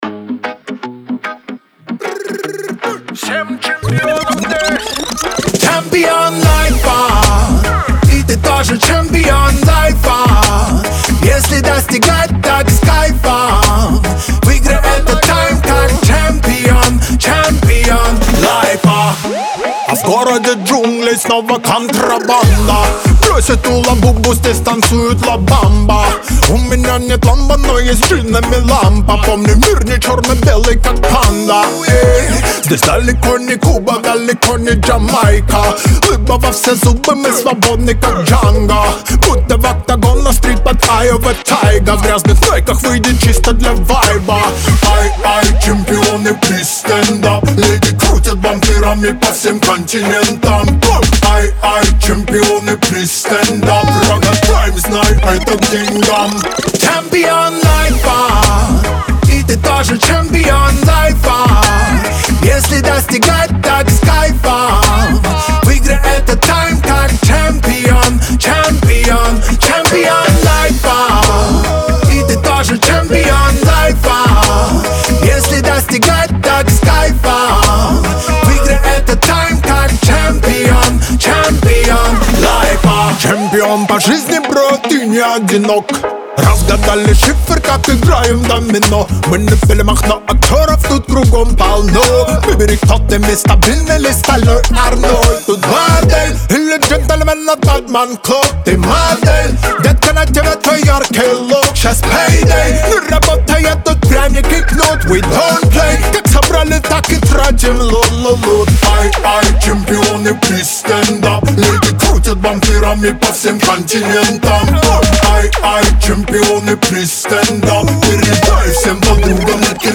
Лирика , праздник , эстрада